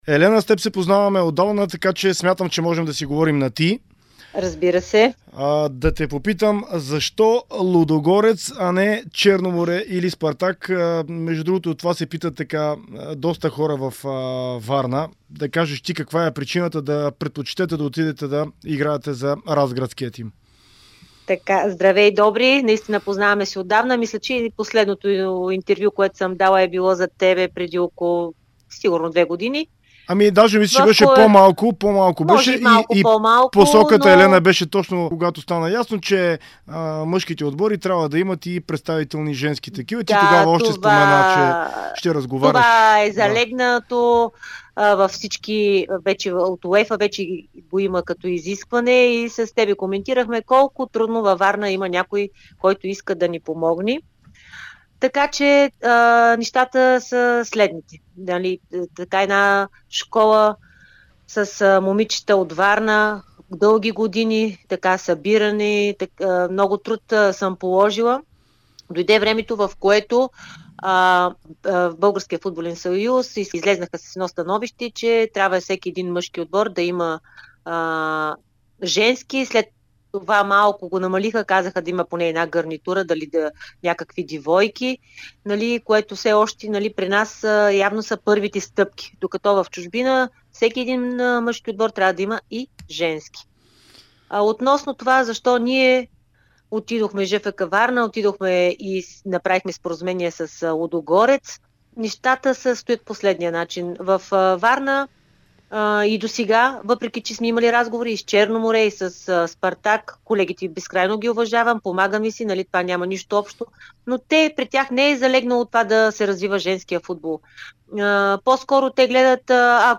говори в интервю за Дарик радио и dsport